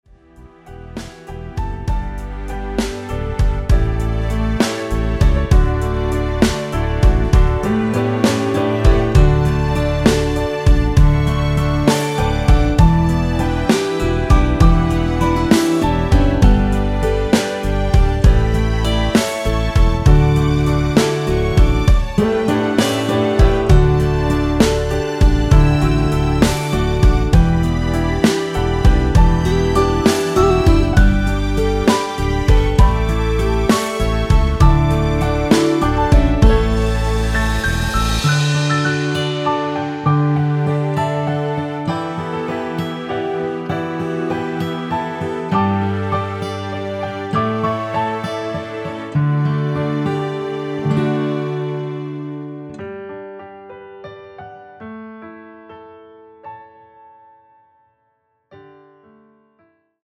고음 부분의 부담없이 부르실수 있게 편곡 하였습니다.
원키에서(-6)내린 Easy Ver. MR 입니다.
앞부분30초, 뒷부분30초씩 편집해서 올려 드리고 있습니다.
중간에 음이 끈어지고 다시 나오는 이유는